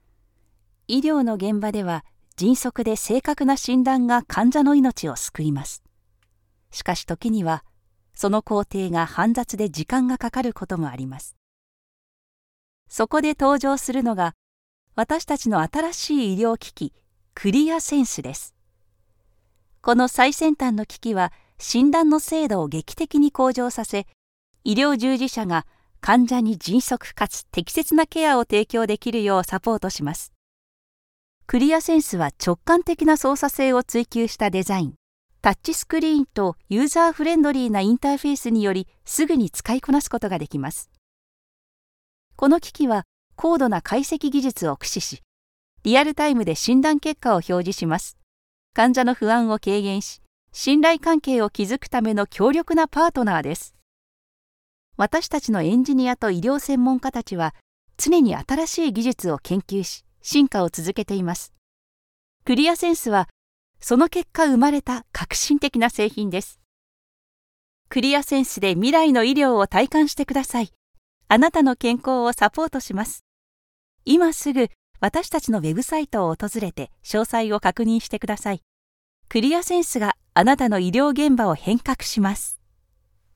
Voz
Natural, Versátil, Seguro, Cálida, Suave
Corporativo